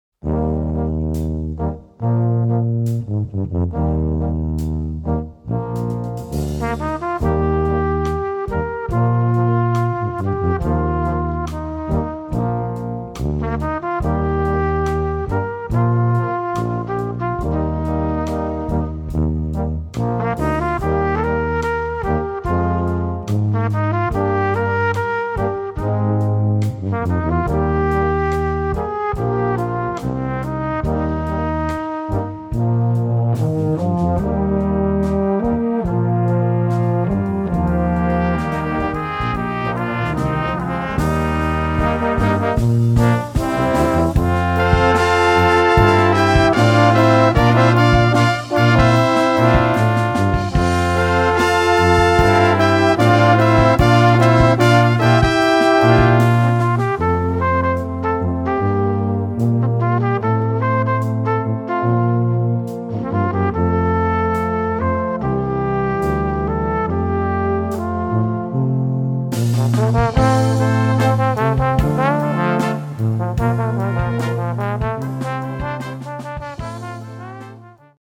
Gattung: Ballade für kleine Besetzung
Besetzung: Kleine Blasmusik-Besetzung